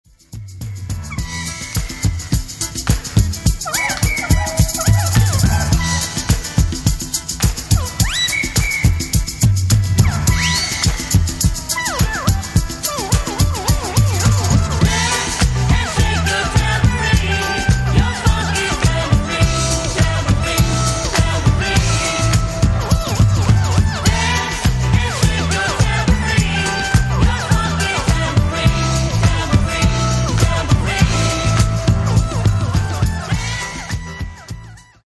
Genere:   Disco | Funk